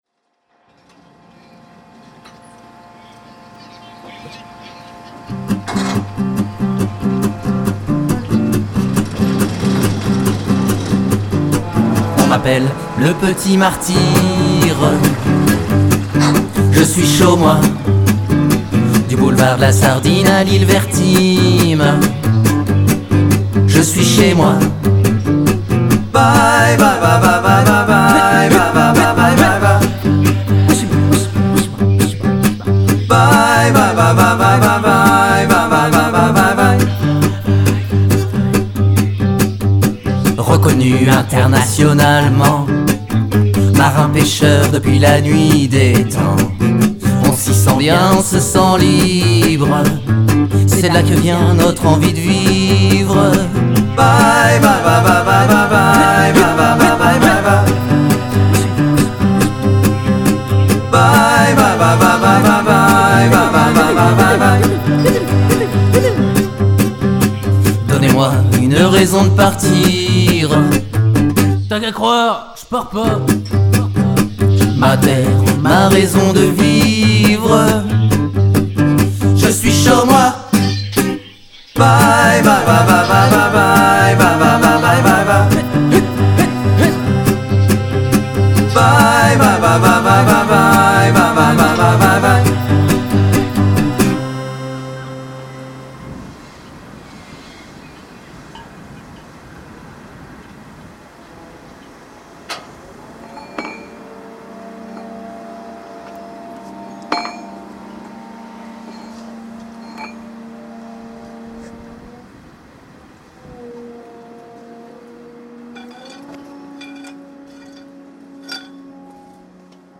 chanson "le petit martyr"